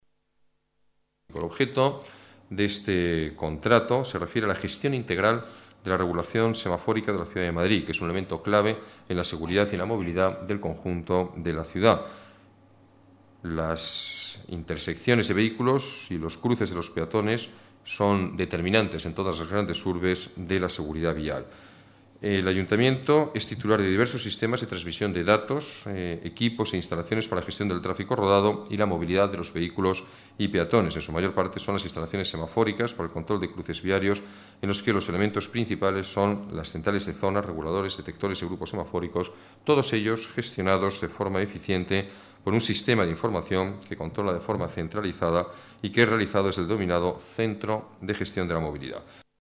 Nueva ventana:Declaraciones de Ruiz-Gallardón sobre regulación de semáforos